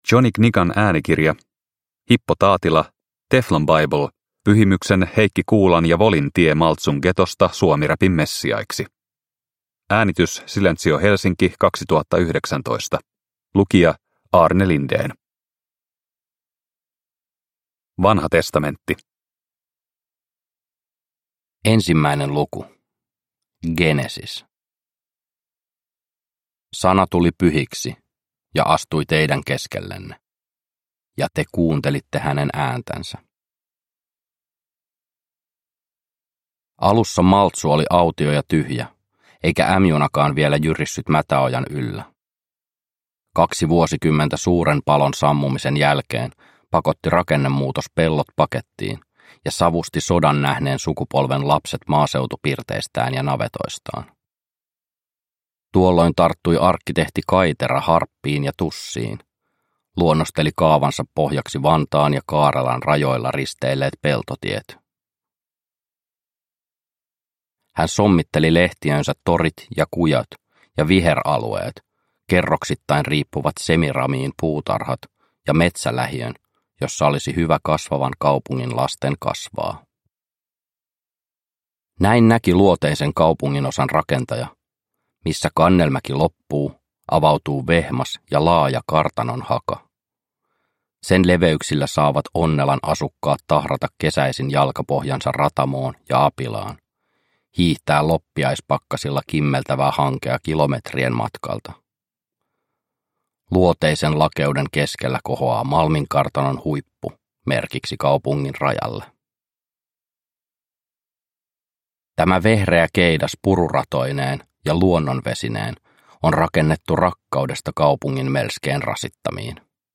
Teflon Bible – Ljudbok – Laddas ner